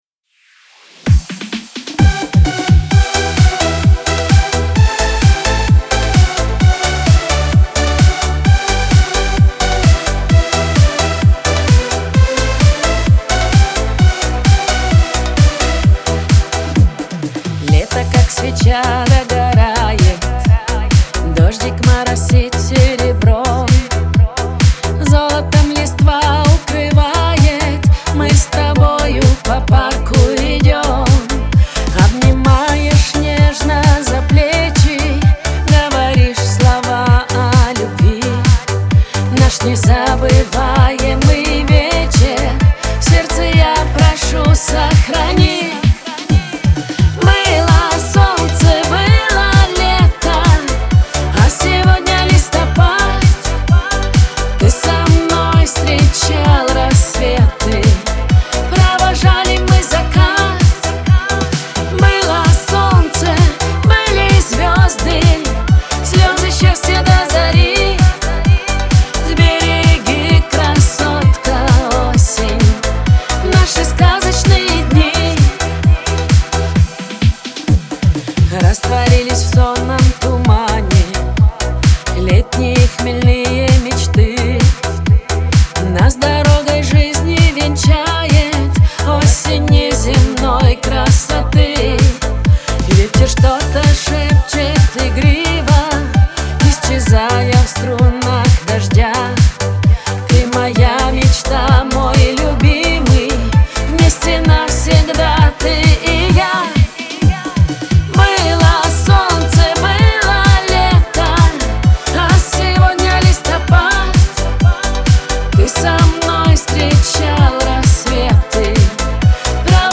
Лирика , грусть , надежда , Шансон